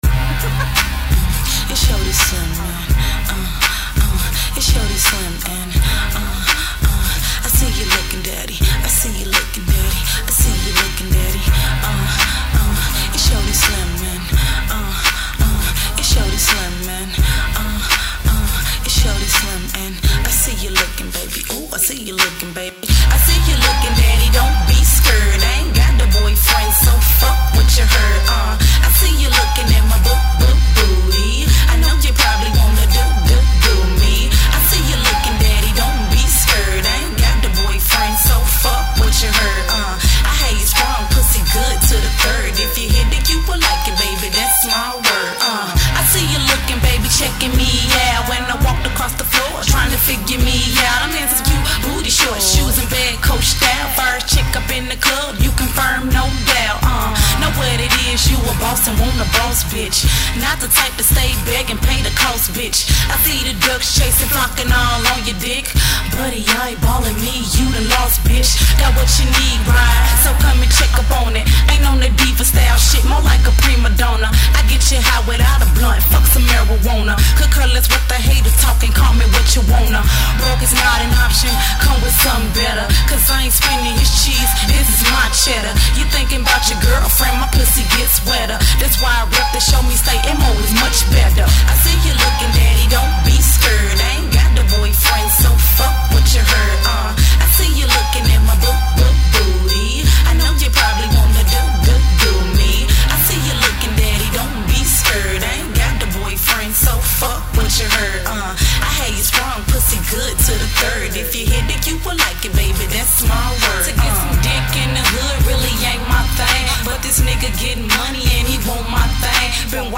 dance/electronic
Hip-hop
Funk